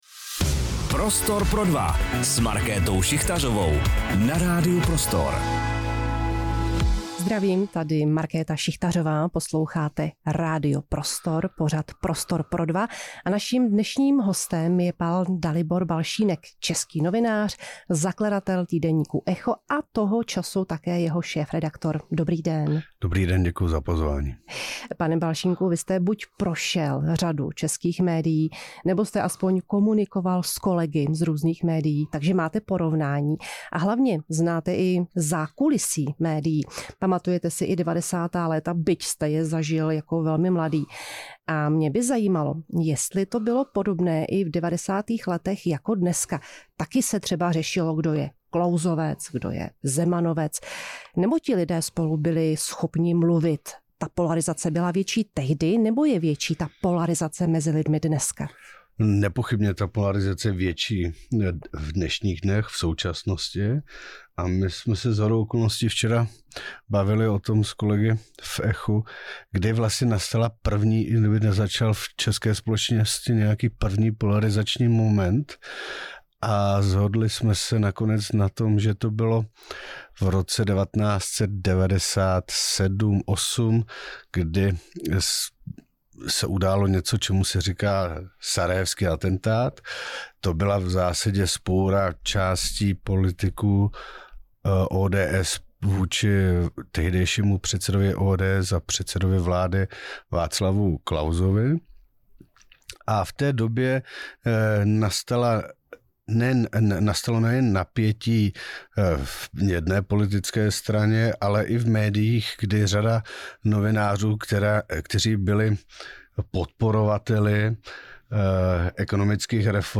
V rozhovoru